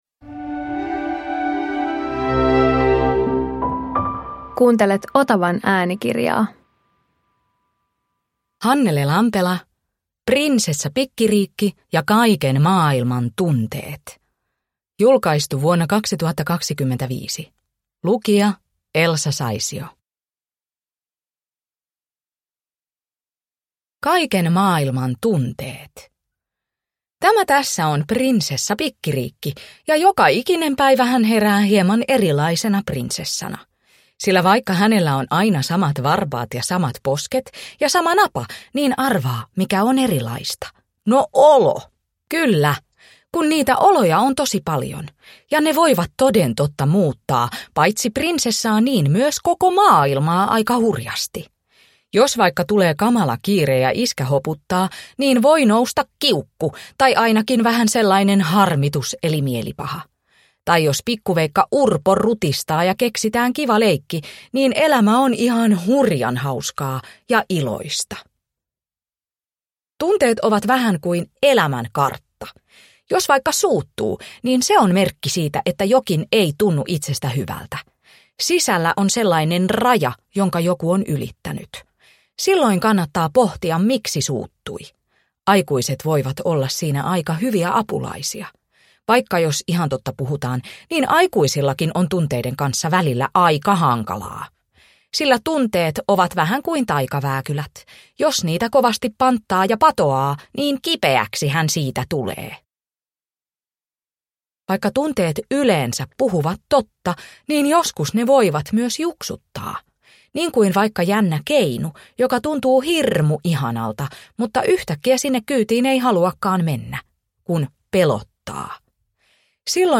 Prinsessa Pikkiriikki ja kaiken maailman tunteet – Ljudbok